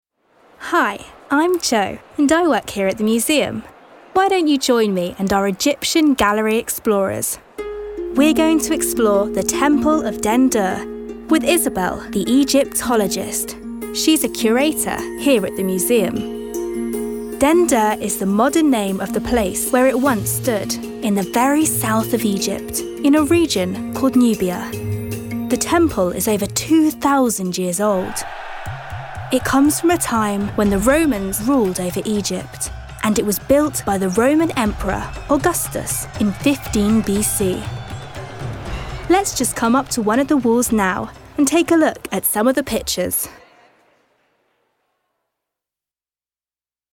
English (British)
Commercial, Young, Cool, Versatile, Friendly
Audio guide